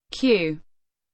alphabet char sfx